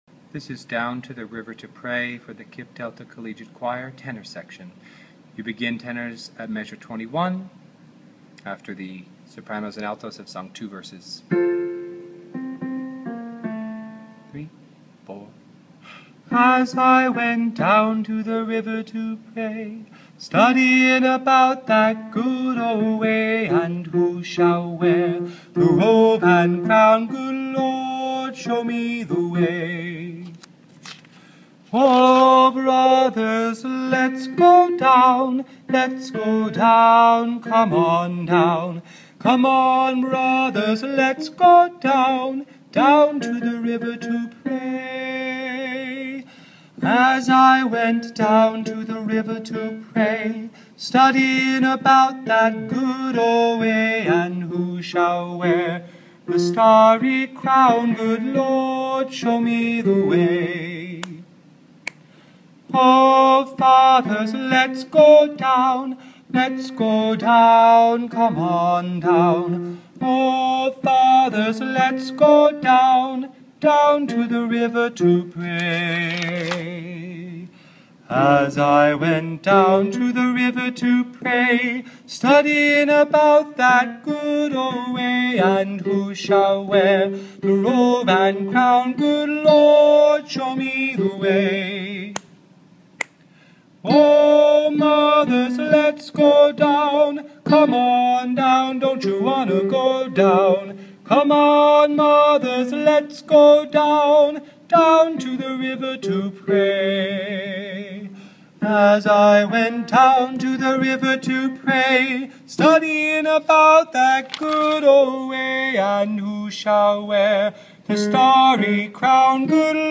Down to the river to pray – Tenor